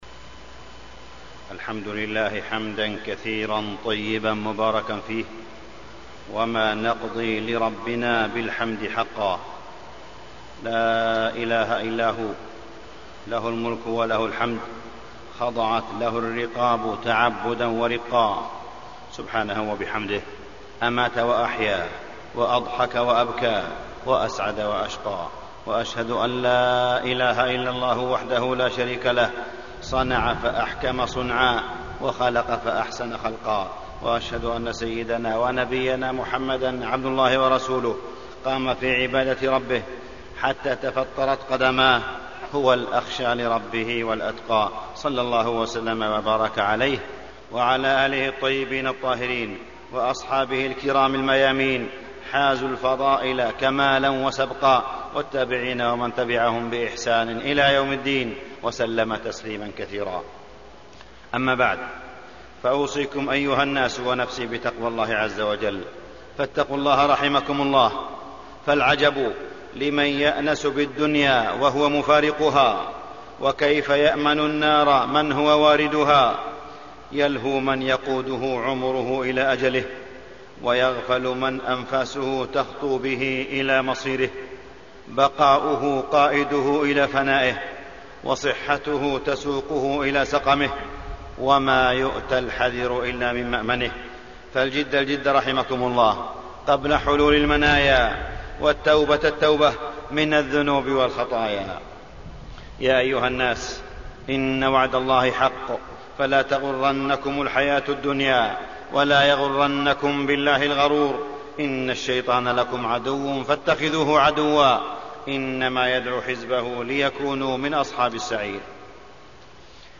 تاريخ النشر ٣ ربيع الثاني ١٤٢٨ هـ المكان: المسجد الحرام الشيخ: معالي الشيخ أ.د. صالح بن عبدالله بن حميد معالي الشيخ أ.د. صالح بن عبدالله بن حميد حصاد الفتن